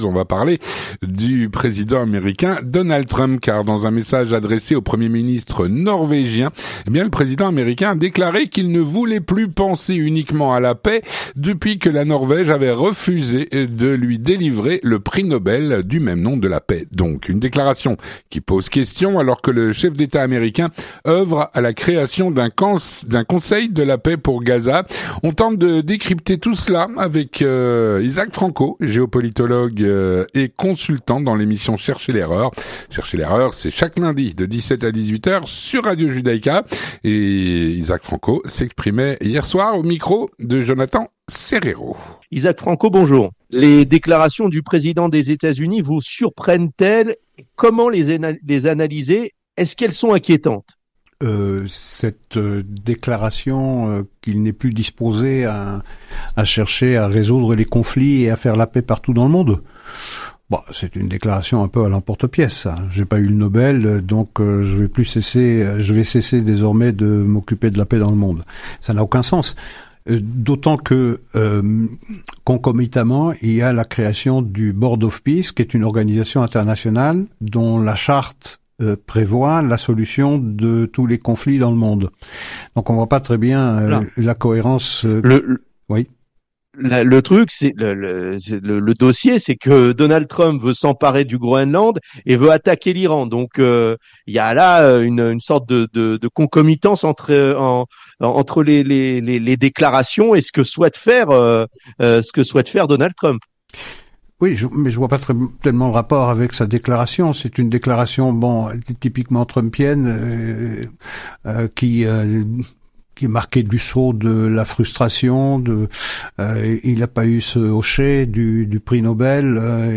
L'entretien du 18H - Le Président Trump a déclaré qu'il ne voulait plus penser uniquement à la paix .